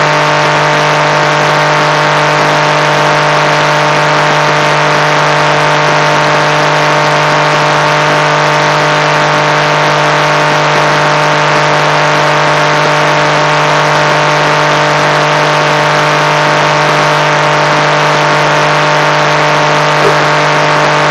speak thinking induction loop set up 7th aug 2015
The frequency analysis shows  a collection of traces near the 15000 hertz.